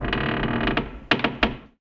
metal_low_creak_squeak_02.wav